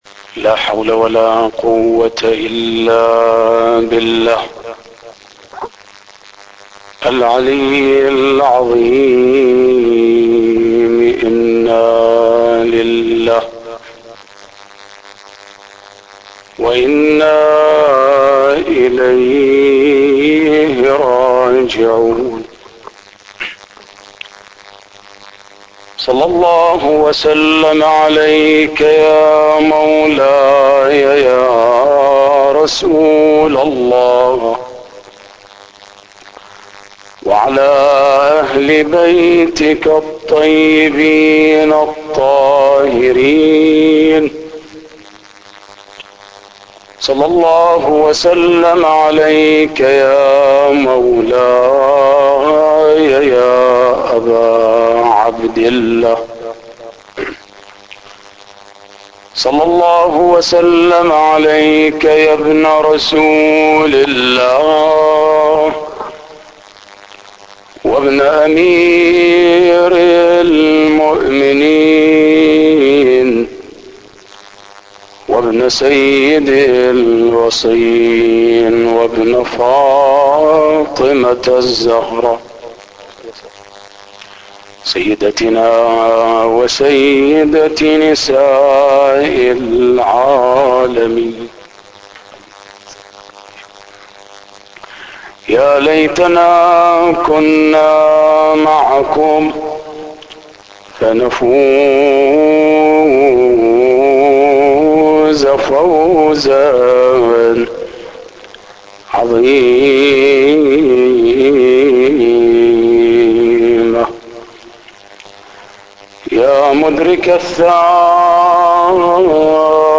مجلس حسيني